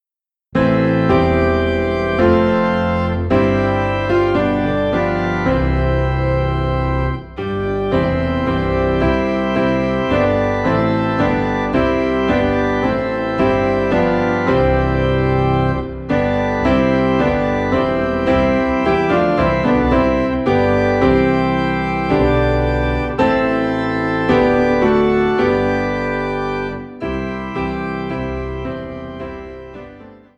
4 in C